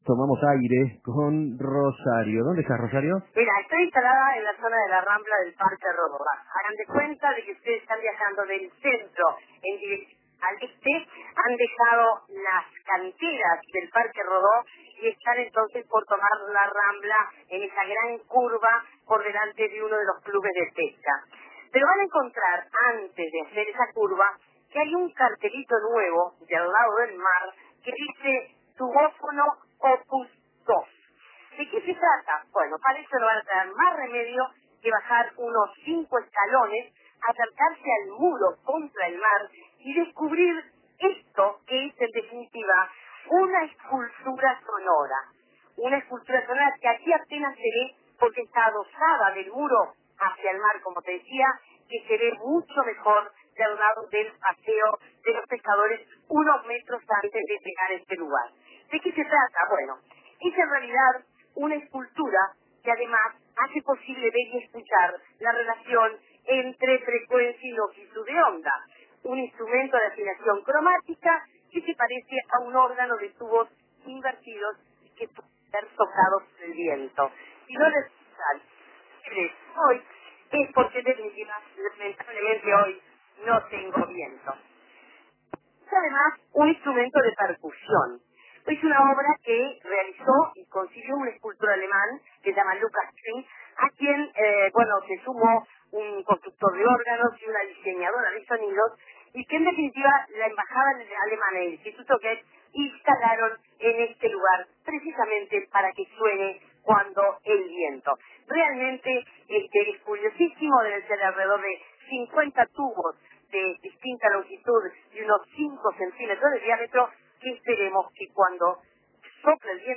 Tubófono Opus 2, escultura sonora en la Rambla del Parque Rodó.